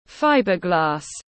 Fibreglass /ˈfaɪbərɡlæs/